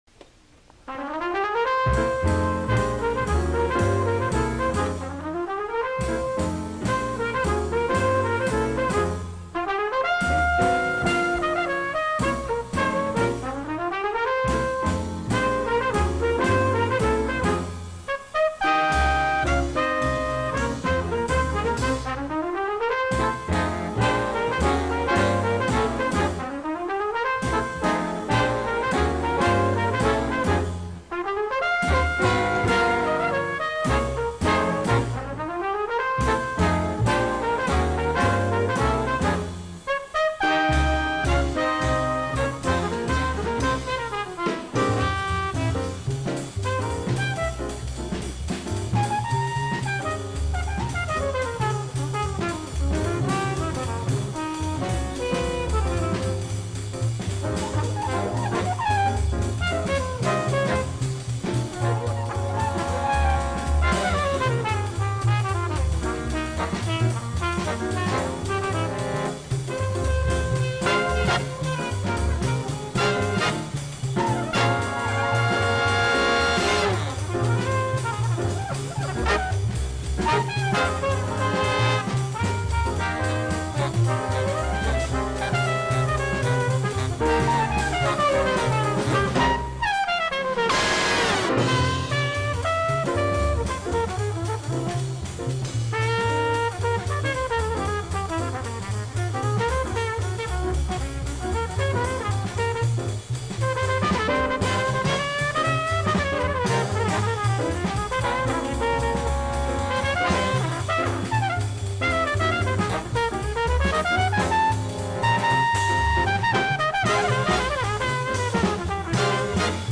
Trumpets
Trombones
Saxes
Guitar
Drums
Bass